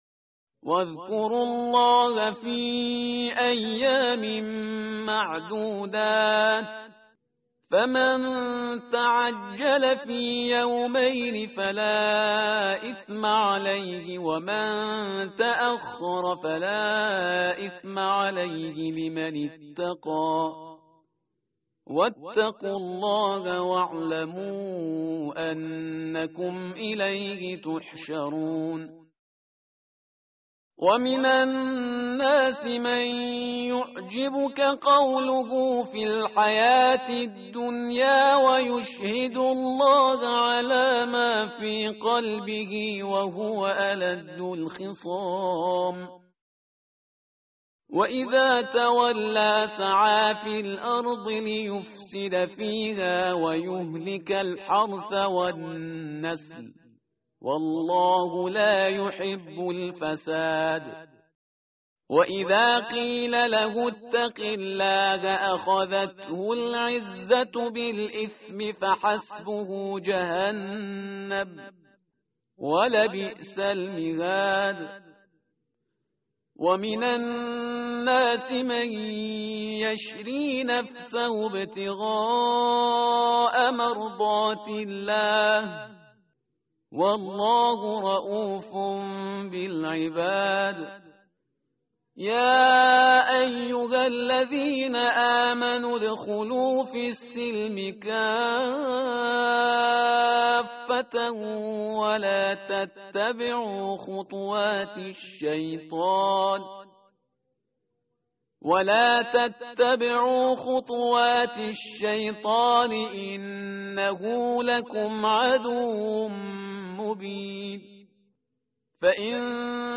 متن قرآن همراه باتلاوت قرآن و ترجمه
tartil_parhizgar_page_032.mp3